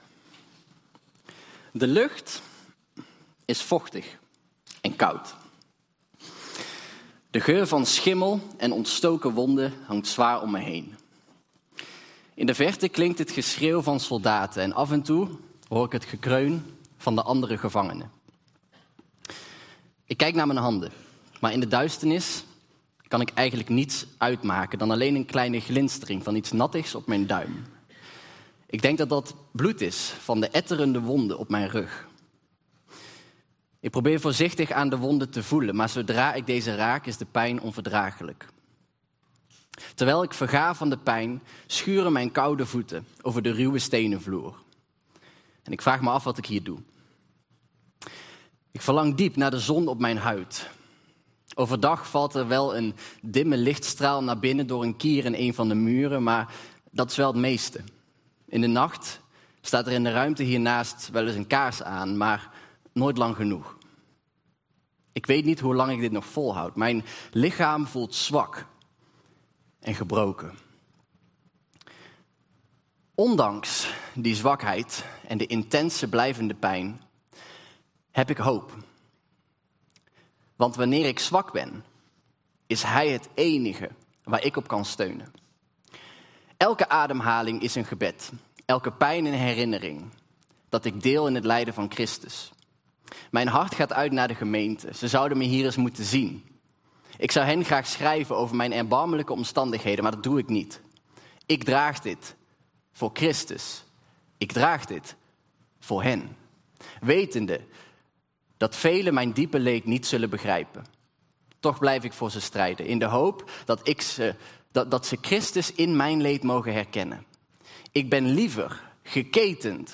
Toespraak 6 april: Paulus, zelfopoffering - De Bron Eindhoven